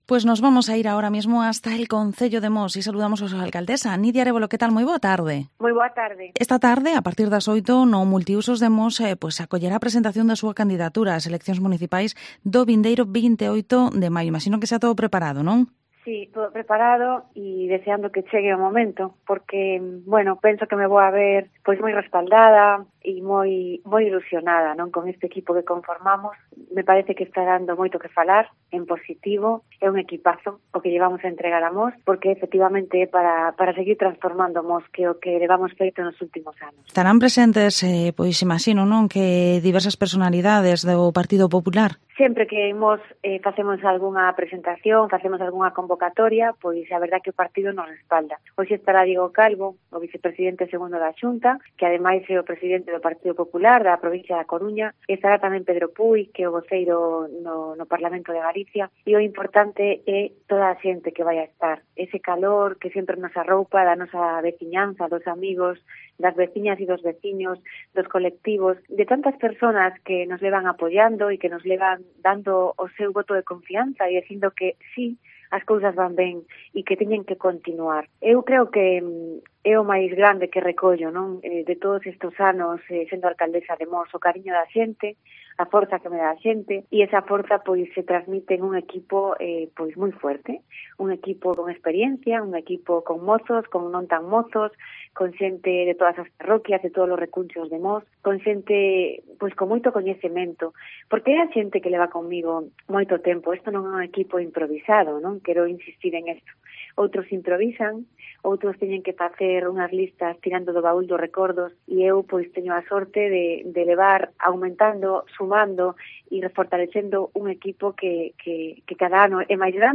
Entrevista Alcaldesa de Mos, Nidia Arévalo